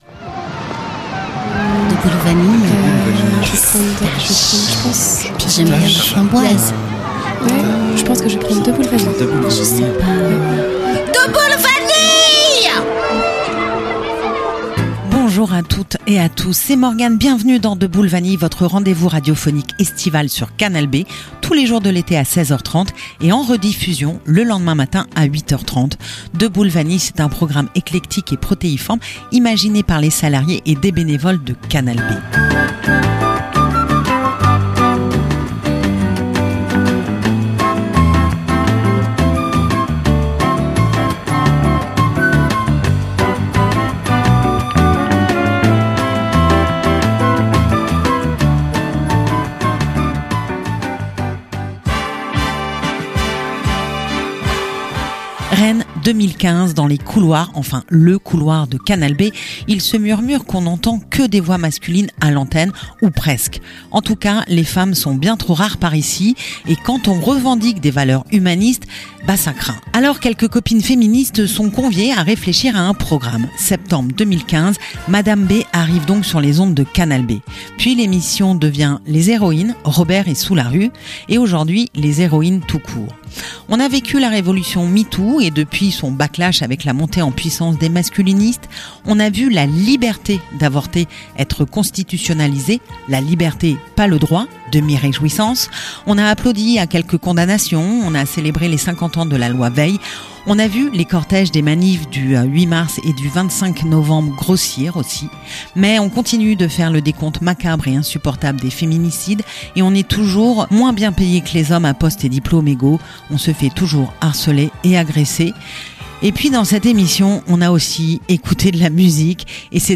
spécial voix féminines et féminisme